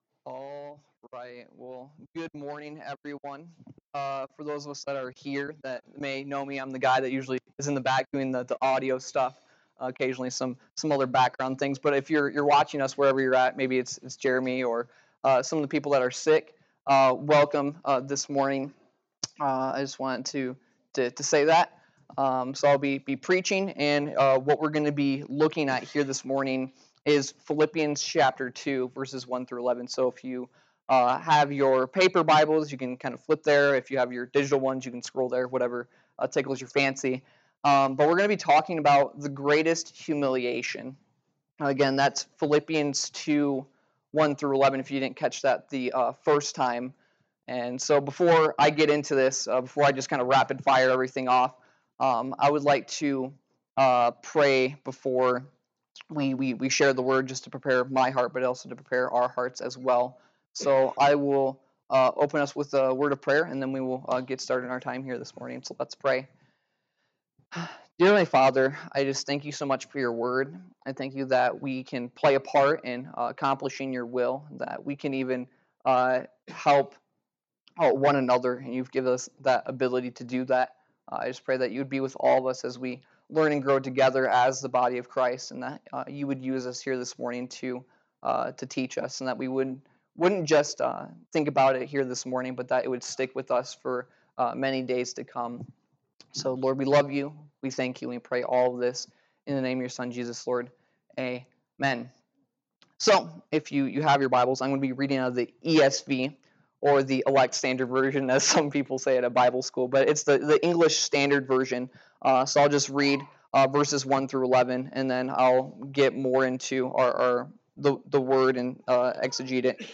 Philippians 2:1-11 Service Type: Sunday Morning Worship « 1 John 3:11-18